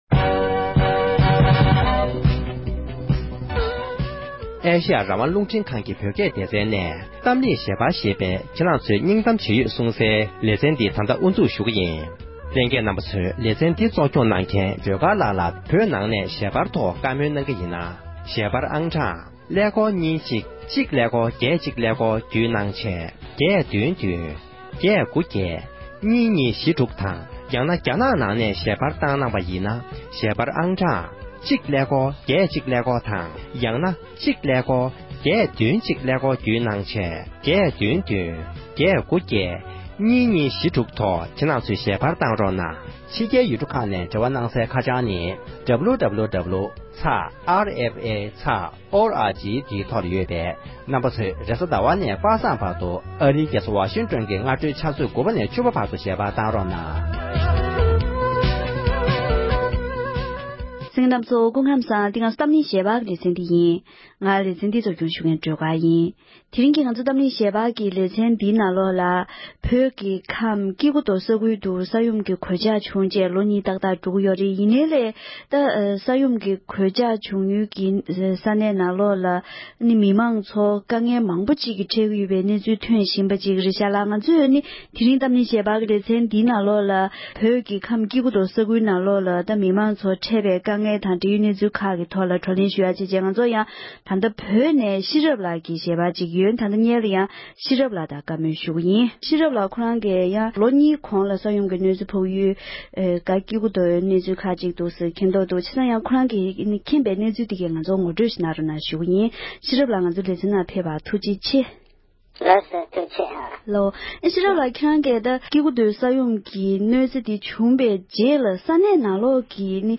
བོད་ཕྱི་ནང་གཉིས་སུ་ཡོད་པའི་མི་སྣ་དང་བཀའ་མོལ་ཞུས་པར་གསན་རོགས་ཞུ༎